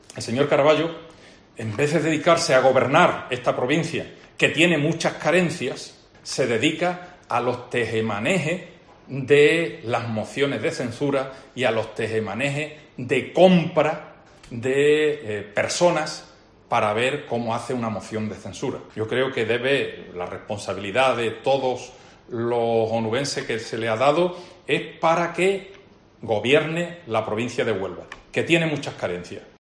Carmelo Romero , diputado nacional del PP
A preguntas de los periodistas en rueda de prensa, Romero se ha referido al anuncio del portavoz del Grupo Municipal Socialista en el Ayuntamiento de Cartaya, Alexis Landero, de dar "un paso a un lado" para que ésta prospere en el pleno que se celebrará el próximo lunes, 6 de julio.